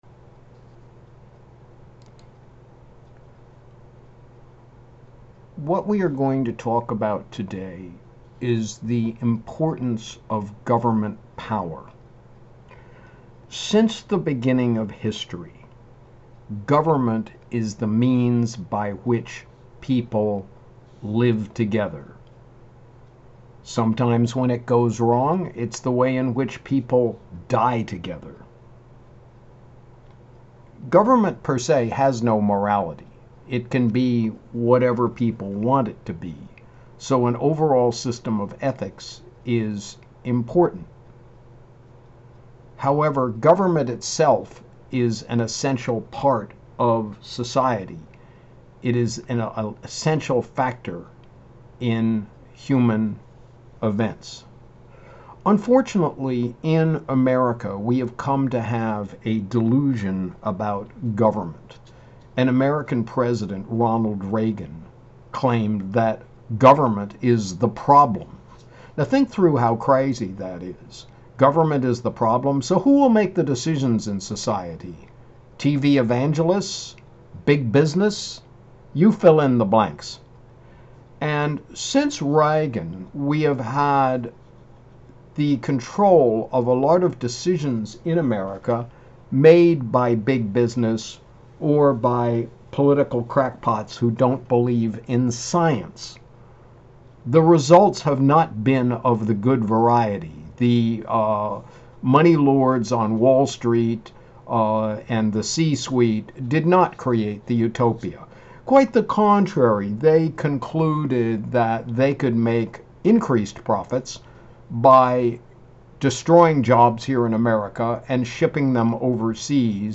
THE IMPORTANCE OF STATE POWER ITS ESSENTIAL ROLE IN SOCIETY THE CRITICAL ROLE OF THE CIVIL SERVICE THE NEED FOR EXCELLENCE IN GOVERNMENT THE NEED TO PAY AND RESPECT CIVIL SERVANTS CLICK HERE FOR LECTURE